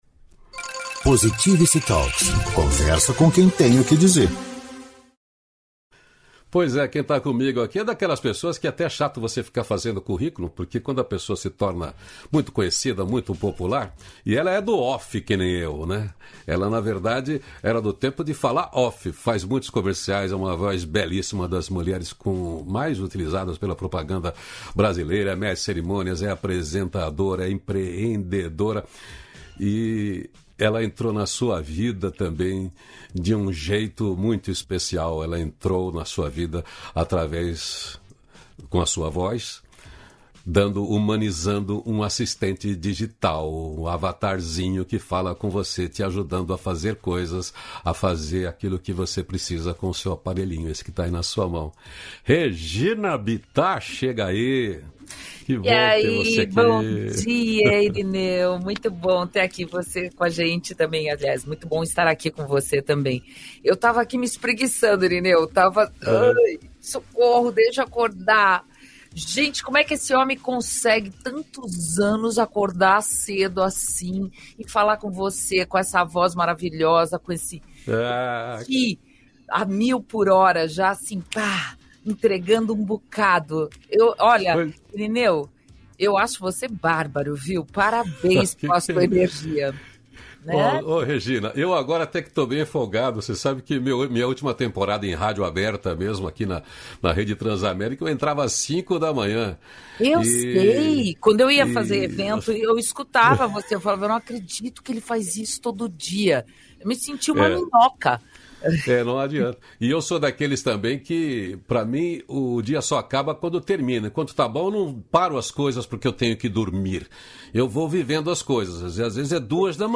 272-feliz-dia-novo-entrevista.mp3